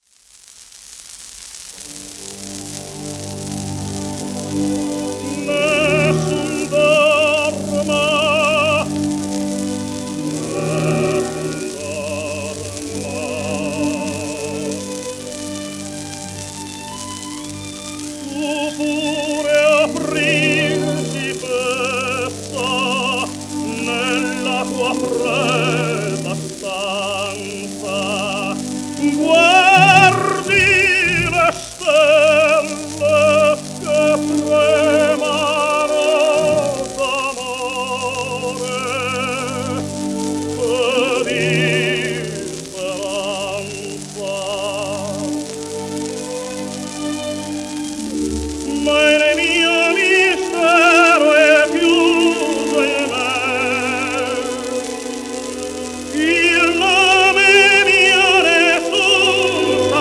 シェルマン アートワークスのSPレコード